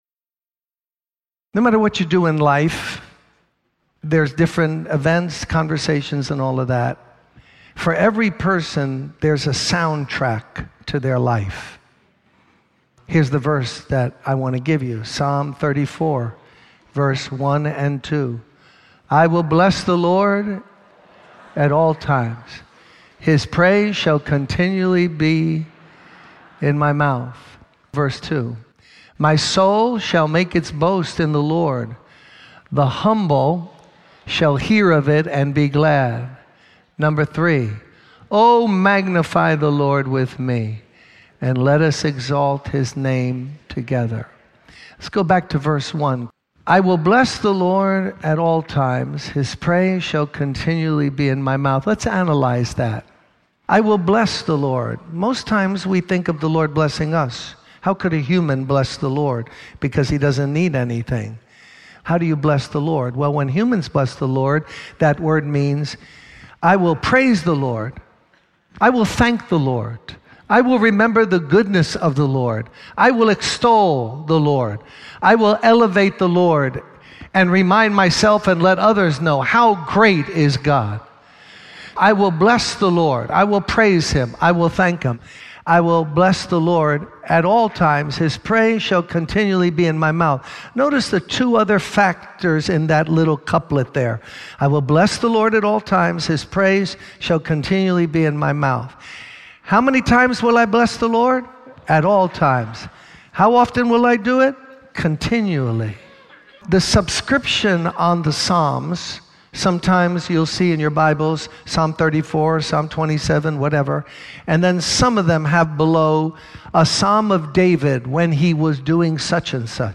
In this sermon, the speaker discusses the importance of not making hasty decisions when under pressure.